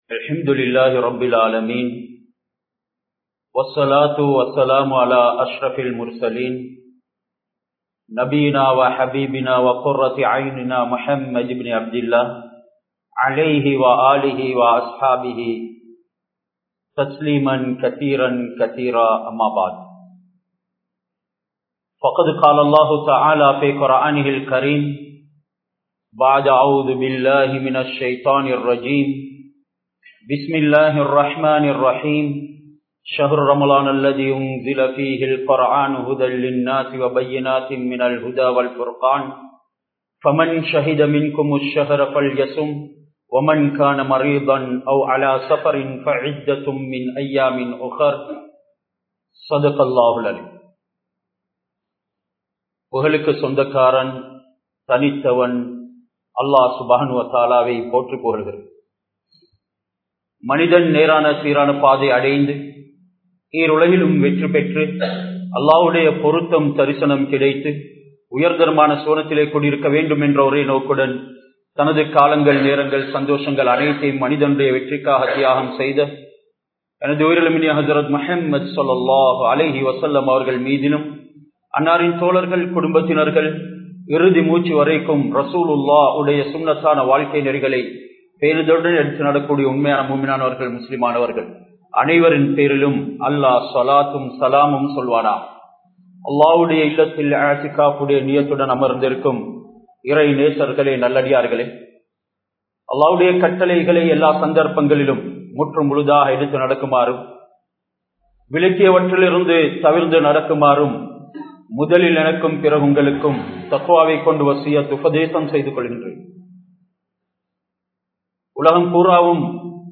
Ramalanum Naattin Nilamium (ரமழானும் நாட்டின் நிலைமையும்) | Audio Bayans | All Ceylon Muslim Youth Community | Addalaichenai
Uyanwaththa Noor Jumua Masjdh